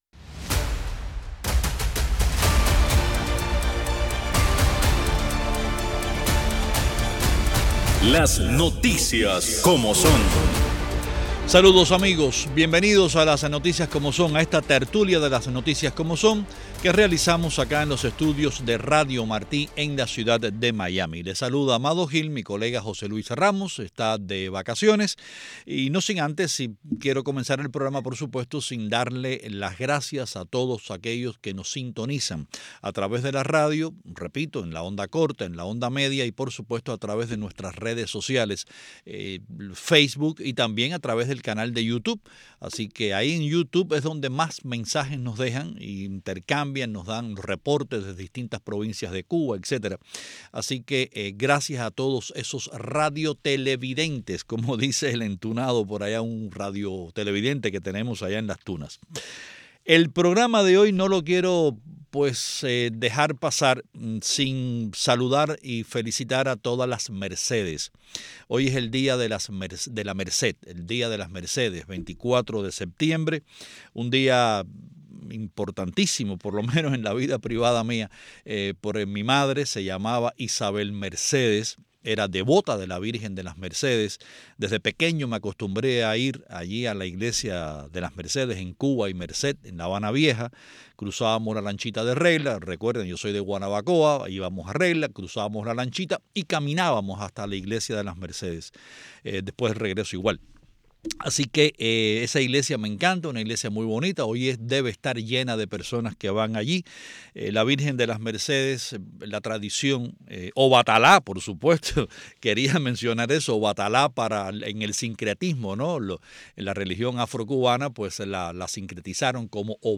Tertulia